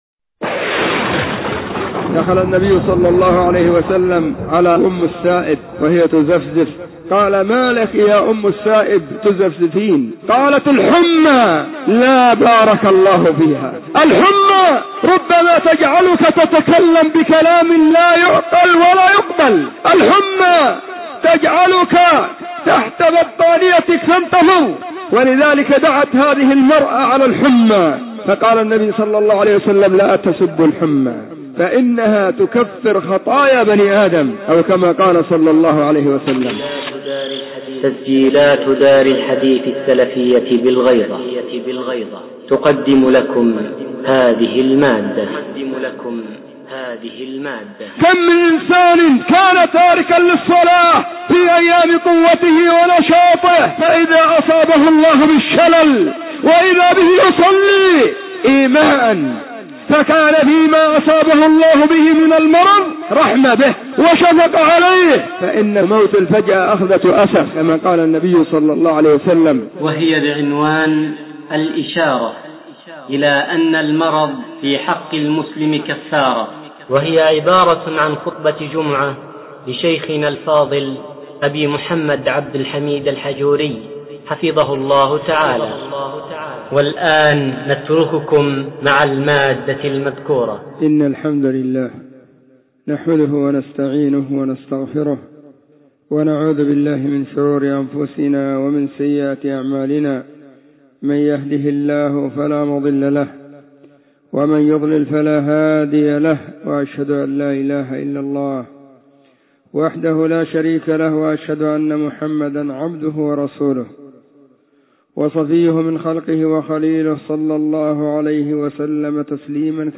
خطبة جمعة بعنوان :الإشارة إلى أن المرض في حق المسلم كفارة
📢 وكانت في مسجد الصحابة بالغيضة محافظة المهرة – اليمن.